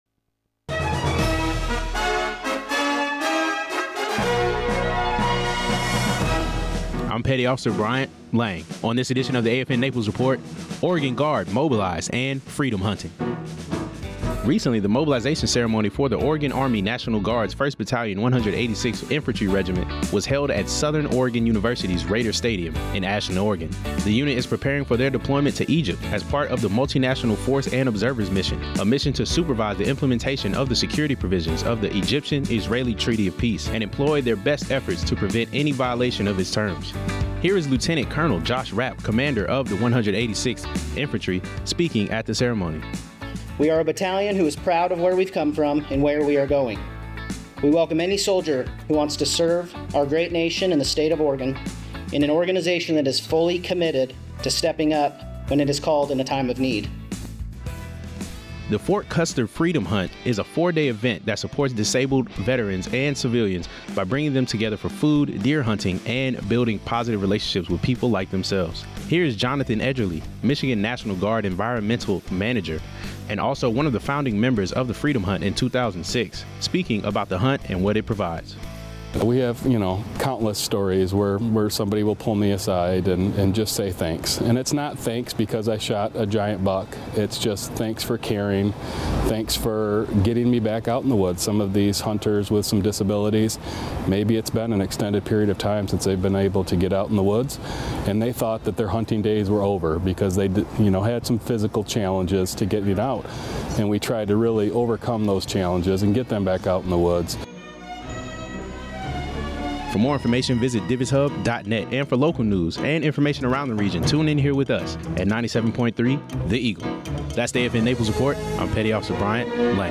Regional news highlighting the Freedom Hunt for disabled veterans and the Oregon Guard deployment ceremony before their mobilization.